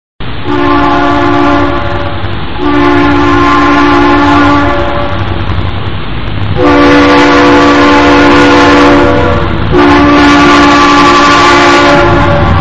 3 – نغمة صوت القطار